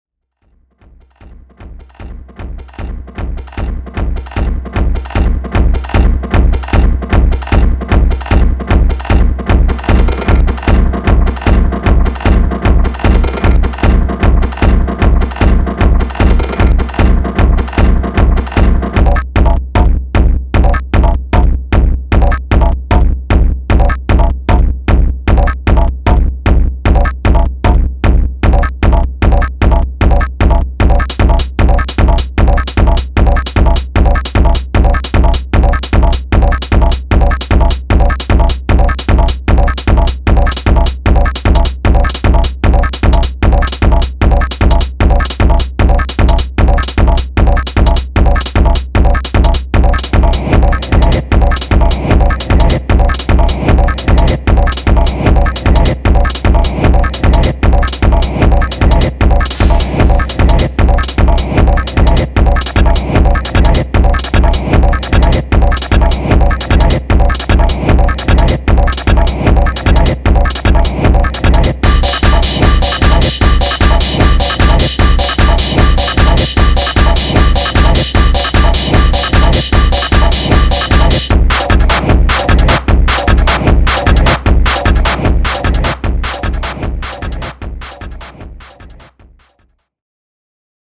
unique breakbeat chaos
Electronix Techno